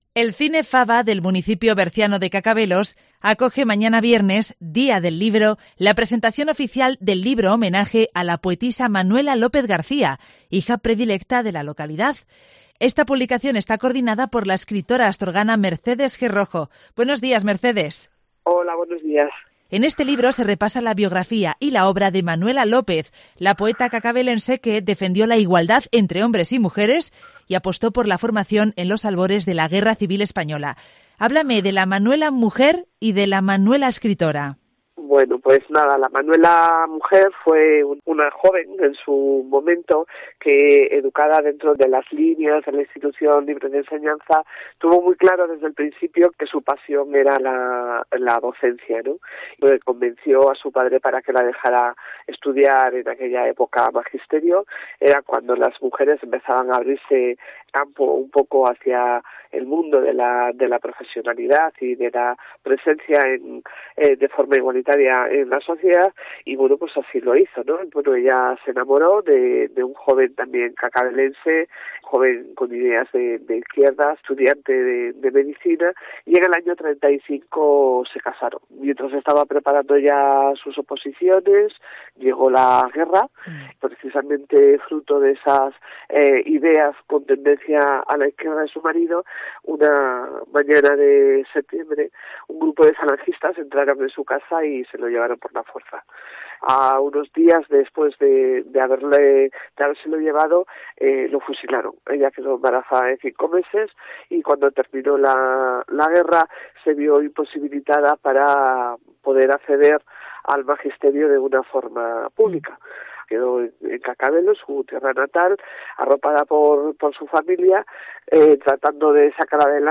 El cine Faba acoge este viernes la presentación del libro que investiga sobre la vida y la obra de esta poeta de la tierra. Escucha aquí la entrevista